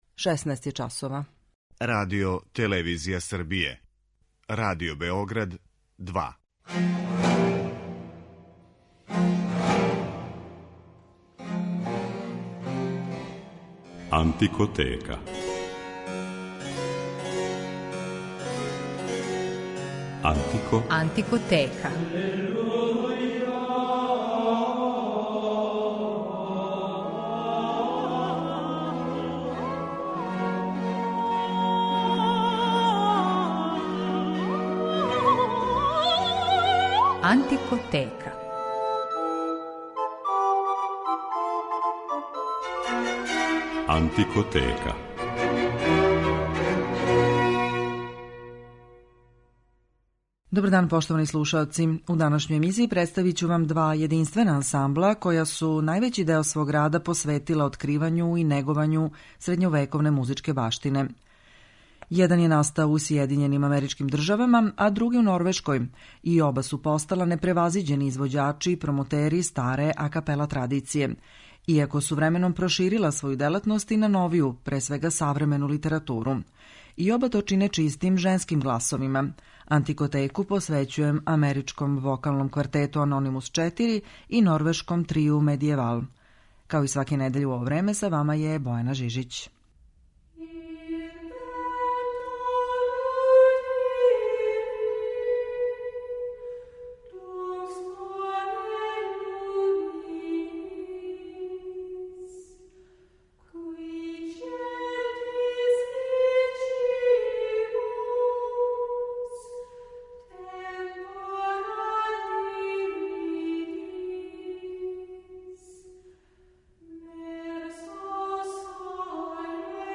вокални квартет